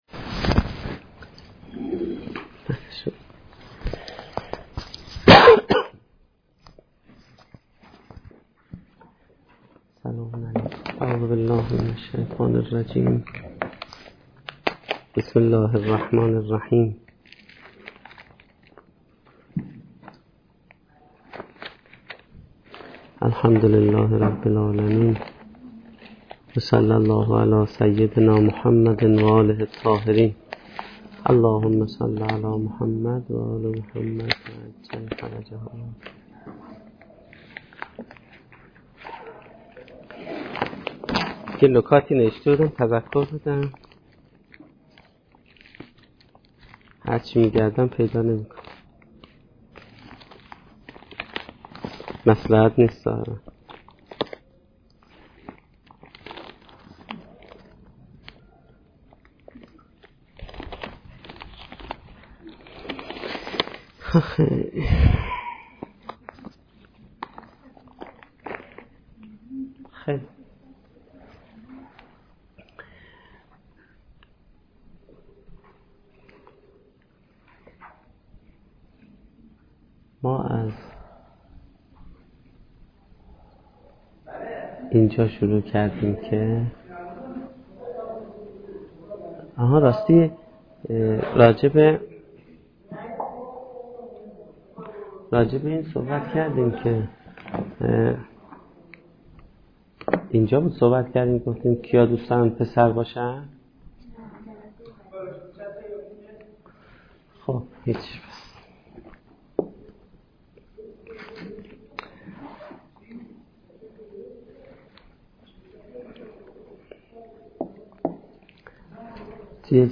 سخنرانی
در دانشگاه فردوسی با موضوع طراحی سرنوشت